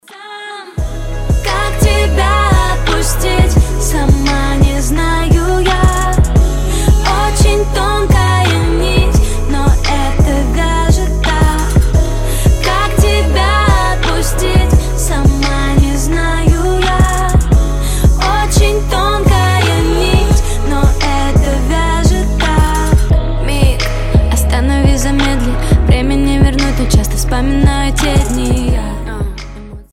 • Качество: 256, Stereo
женский вокал
Хип-хоп
RnB